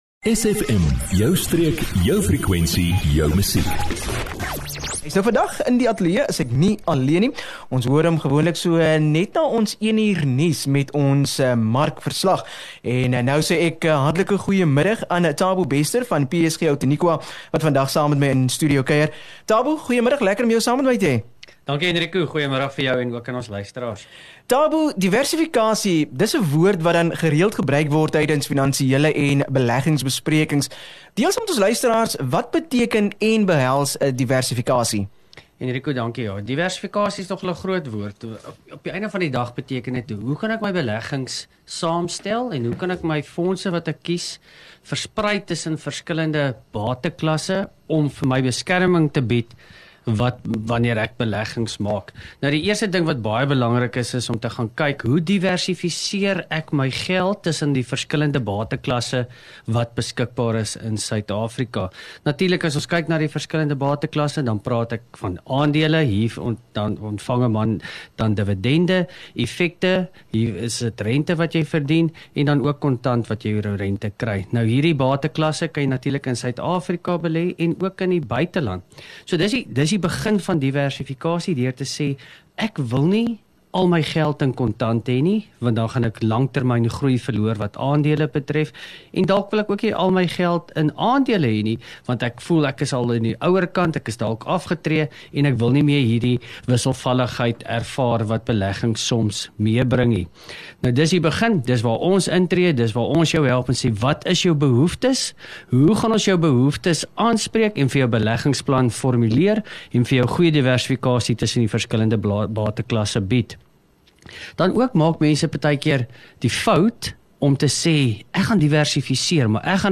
22 Oct PSG Outeniqua onderhoud 22 Oktober 2024